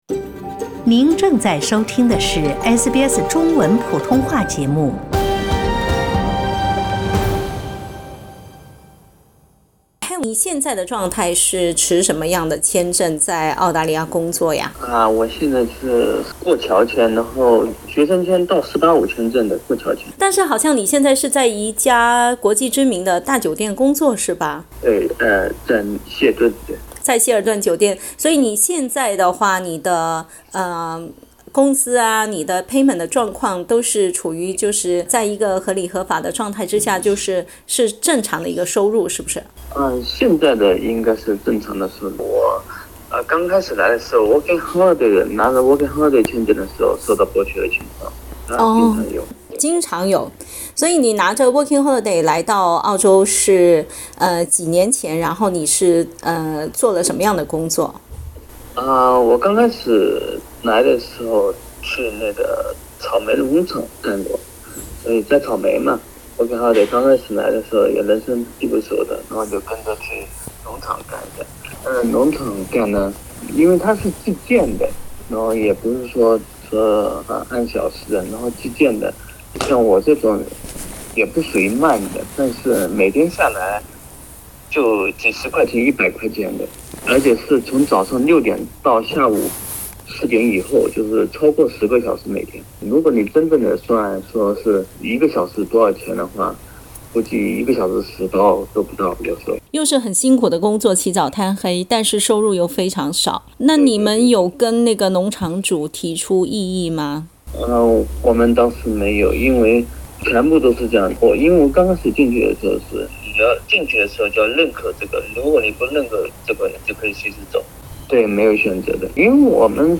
（点击文首图片收听采访） （本文系SBS中文普通话节目原创内容，未经许可，不得转载。